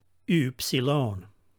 Pronunciation Note: The Upsilon (υ <